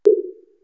200Hz〜400Hzの残響時間が長目で、その原因はフラッターエコーであった。残響音の該当帯域だけを聞けば、ちょっと捩（よじ）れた減衰途中のピッチが揺れる音なので一聴瞭然です。
[400Hzのフラッターエコーの音]。